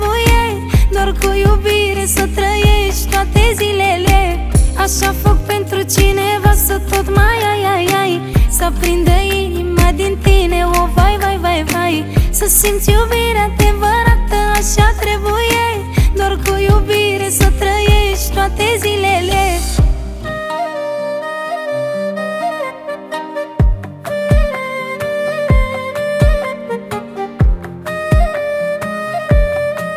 2025-06-25 Жанр: Танцевальные Длительность